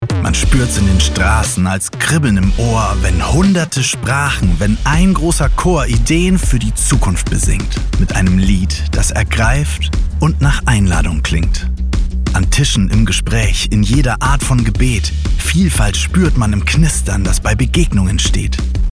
Sprechprobe: Sonstiges (Muttersprache):
Tag Der Deutschen Einheit_TV Spot.mp3